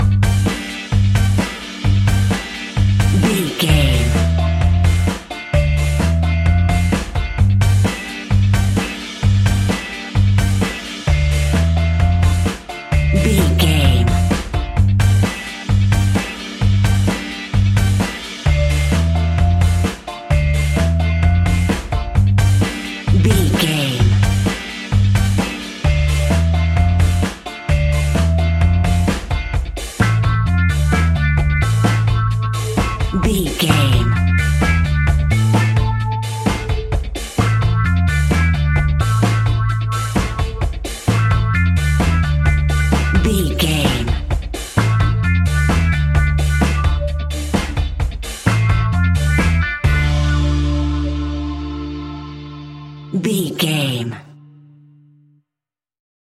Classic reggae music with that skank bounce reggae feeling.
Aeolian/Minor
reggae instrumentals
laid back
chilled
off beat
drums
skank guitar
hammond organ
percussion
horns